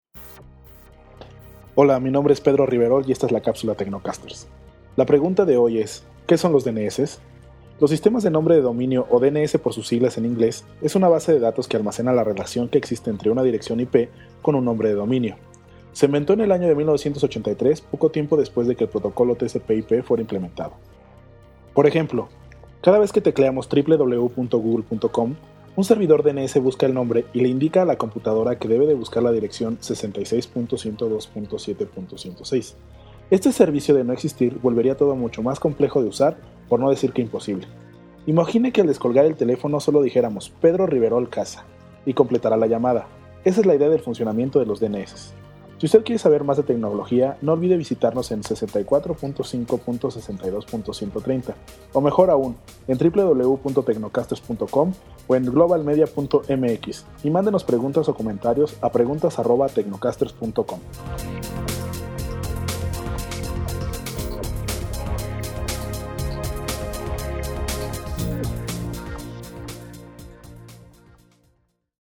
Capsula para transmision en Radio.